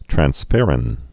(trăns-fĕrĭn)